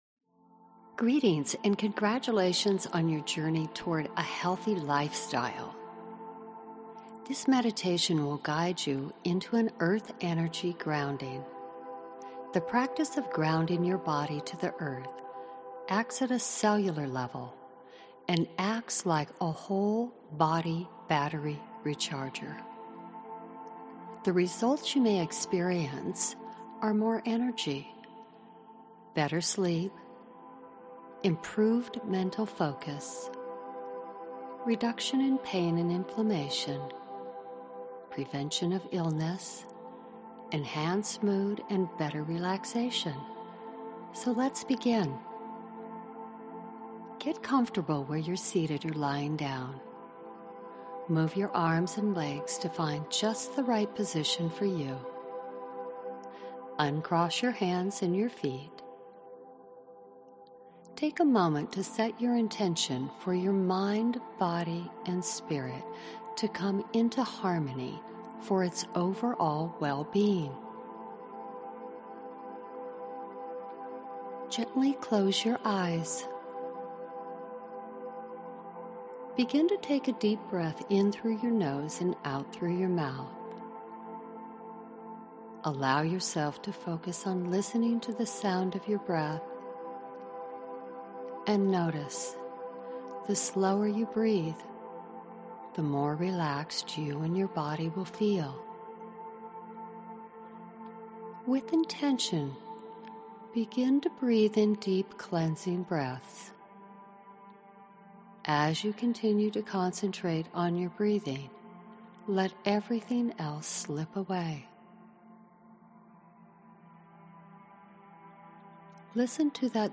Meditations
Meditation+1+-+Ground+And+Center+Your+Energy.mp3